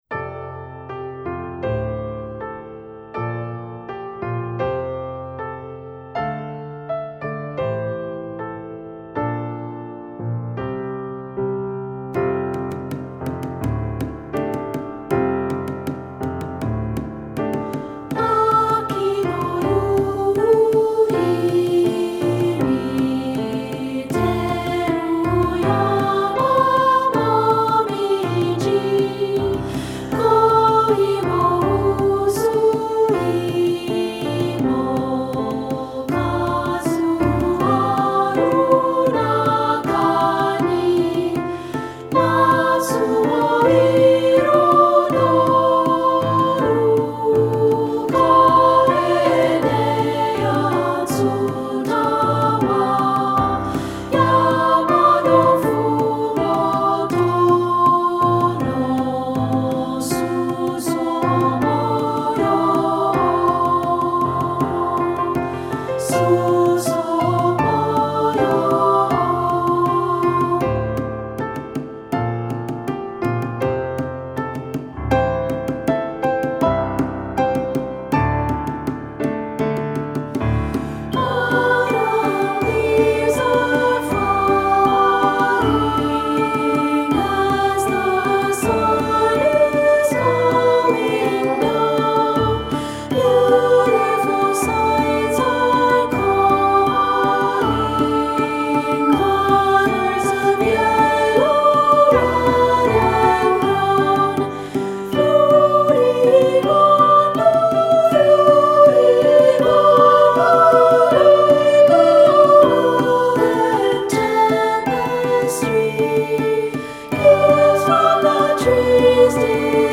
Composer: Japanese Folk Song
Voicing: Two-part